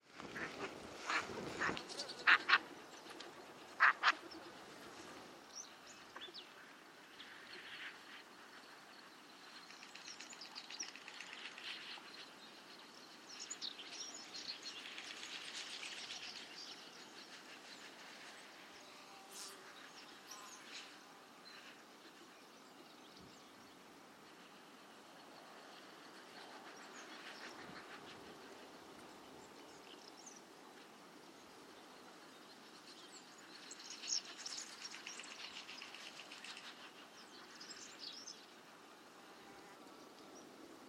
canard-souchet.mp3